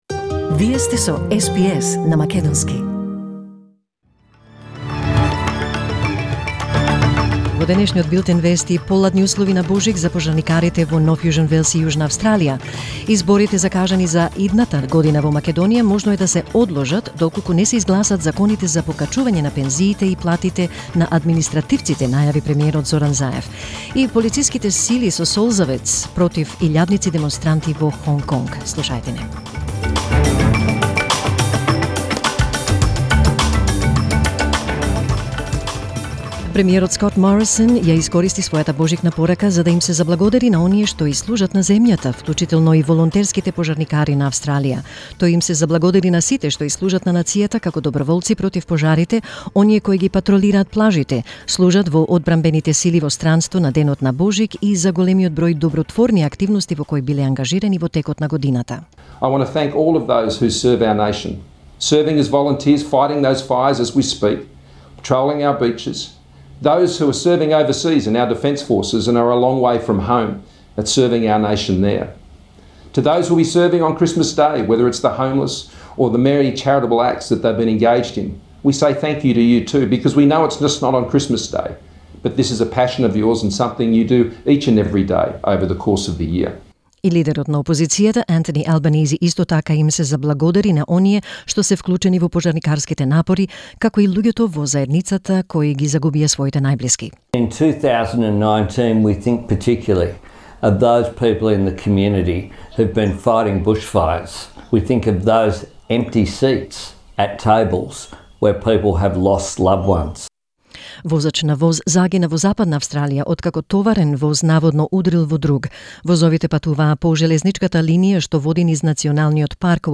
SBS News in Macedonian 25 December 2019